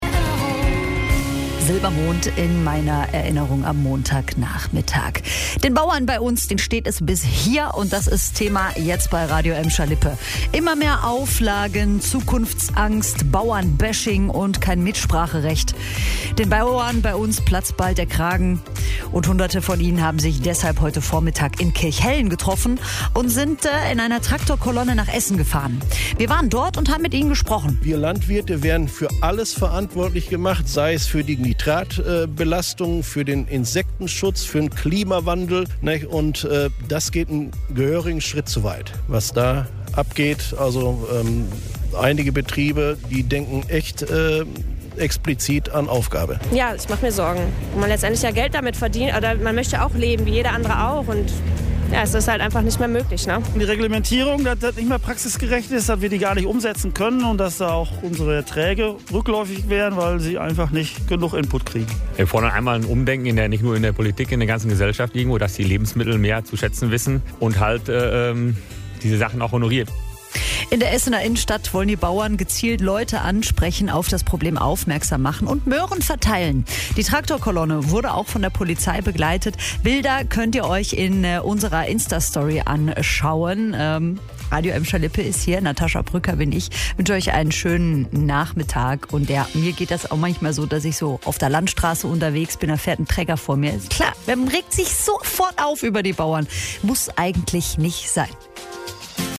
Hunderte von ihnen haben sich deshalb heute Vormittag in Kirchhellen getroffen und sind in einer Traktor-Kolonne nach Essen gefahren. Wir waren dort und haben mit ihnen gesprochen: